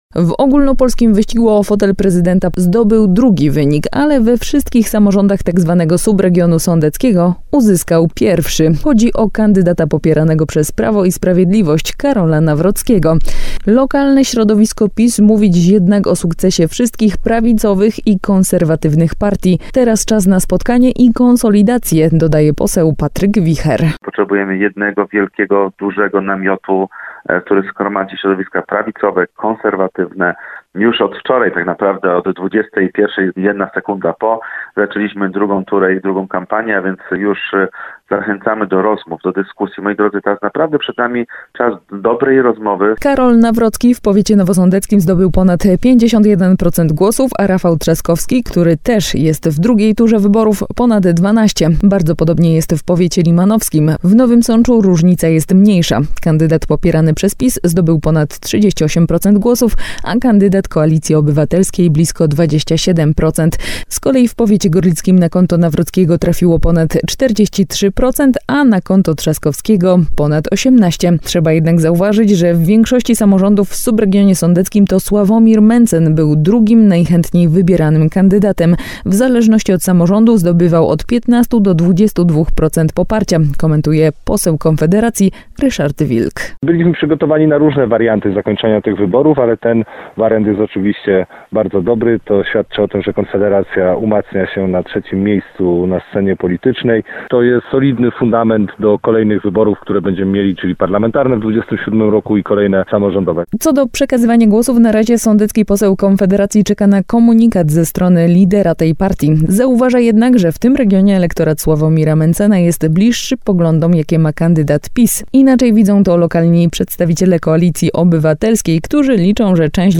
Przyglądamy się danym publikowanym w poniedziałek (19.05) przez Państwową Komisję Wyborczą i wsłuchujemy w komentarz posłów z okręgu 14.